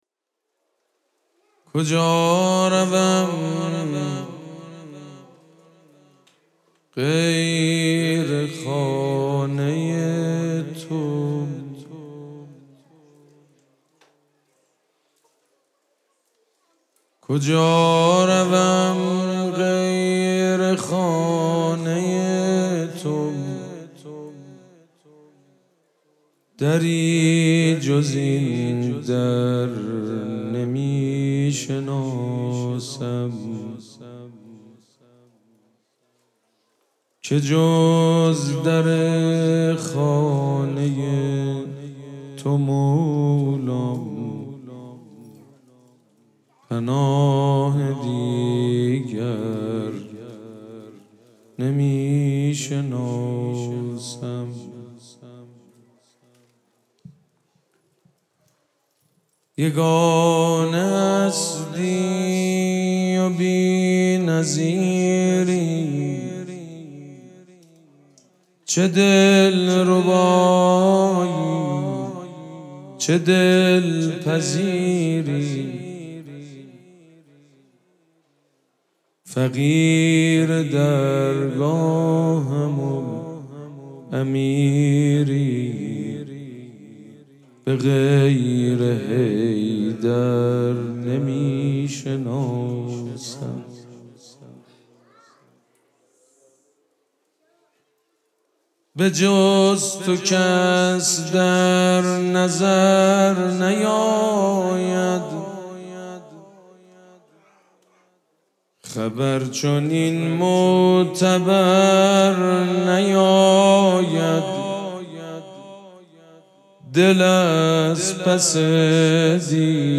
مراسم جشن ولادت حضرت زینب سلام‌الله‌علیها
شعر خوانی
مداح
حاج سید مجید بنی فاطمه